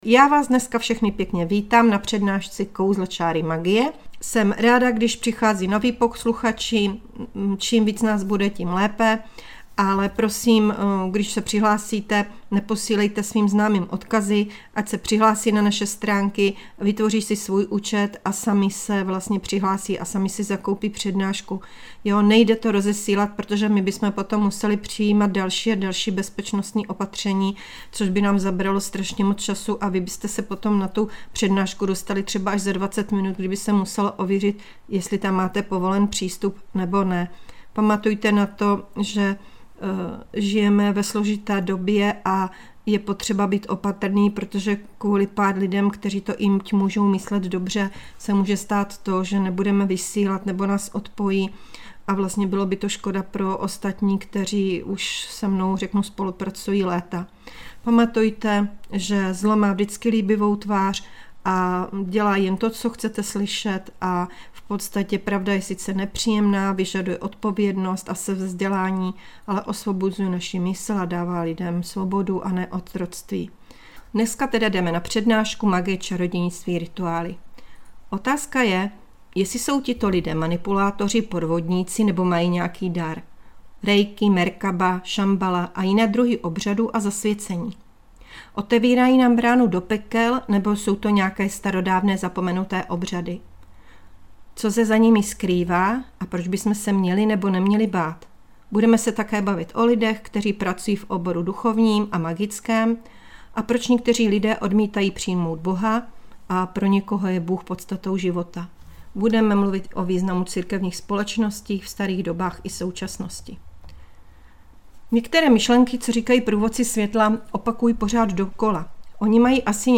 Přednáška Magie, čarodějnictví a rituály - svět mystiky a tajemna